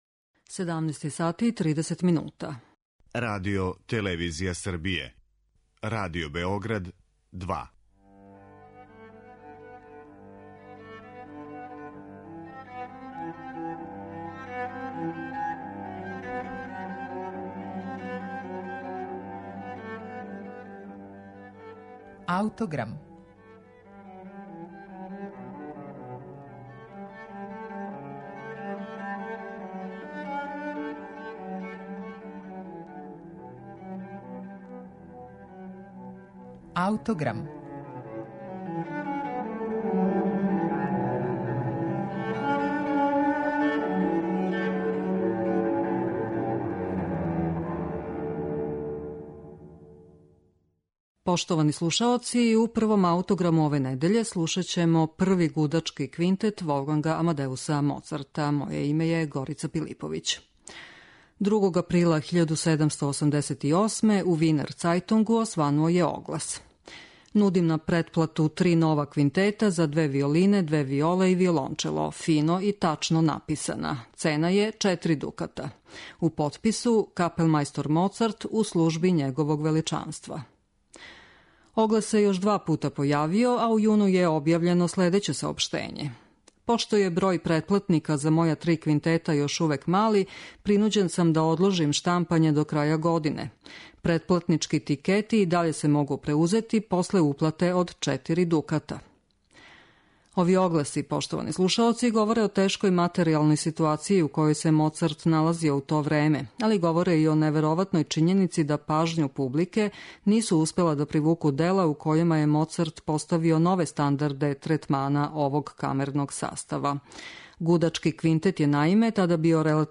Моцартов Први гудачки квинтет